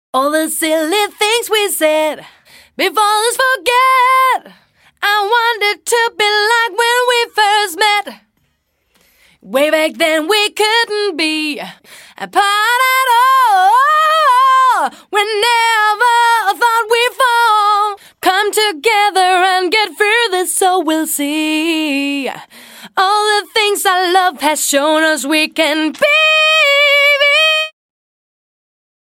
la voix originale sans effets....